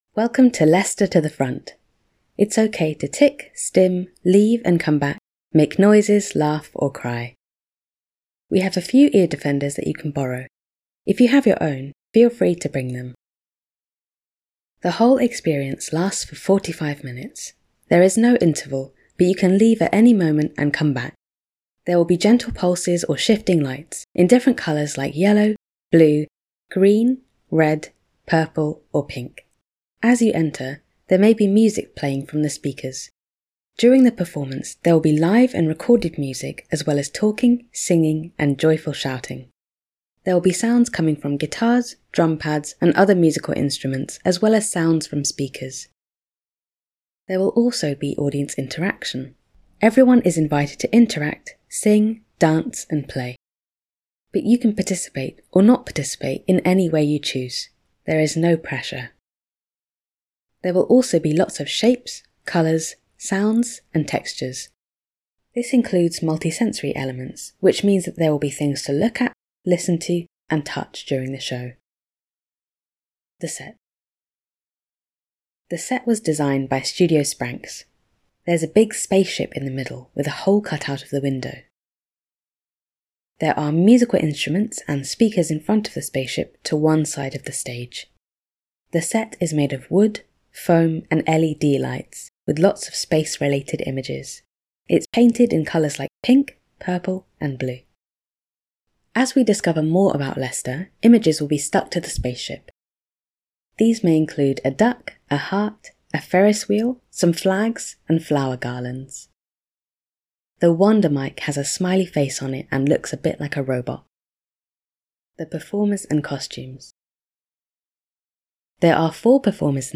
Audio Description
4.-Audio-Description-MP3-Les-tah-to-the-Front.mp3